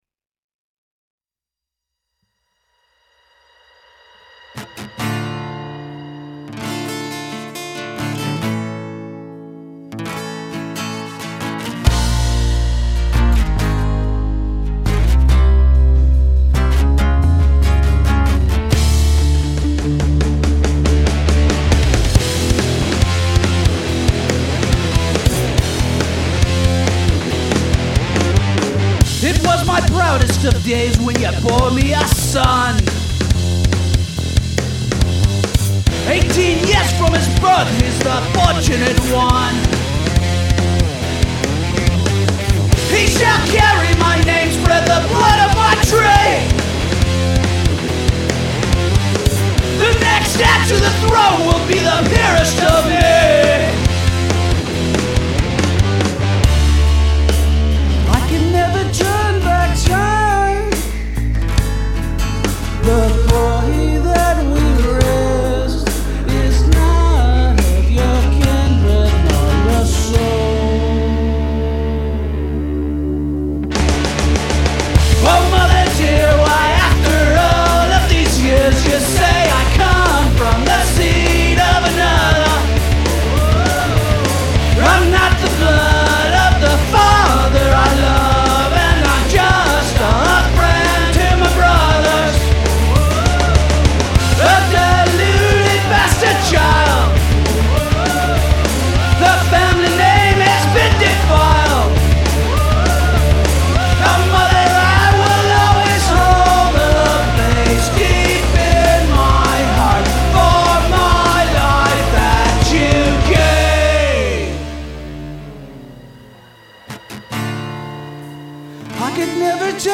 Must include three different tempos
Get female singer for mother.